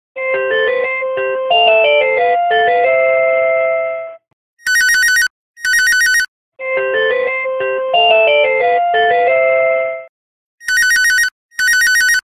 Klassisk Telefon, Klassisk, Android